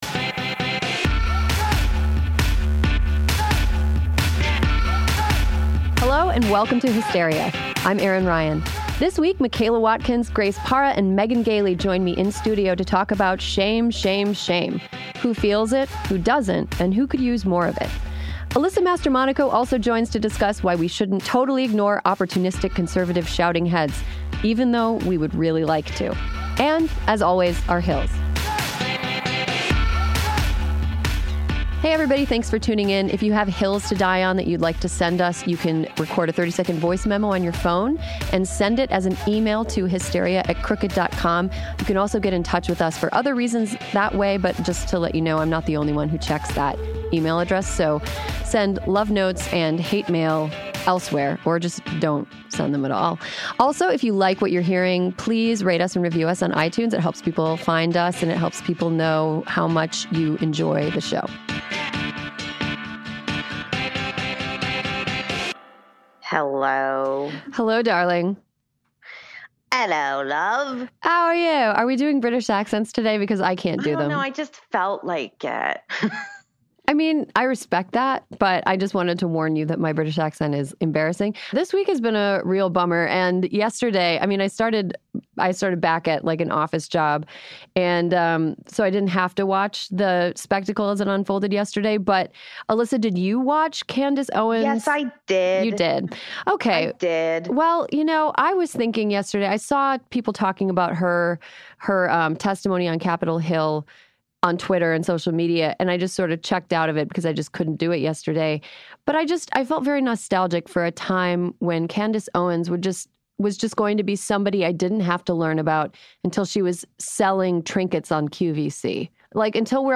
Plus, Alyssa Mastromonaco calls into discuss how fringe voices on the right are suddenly more mainstream than ever.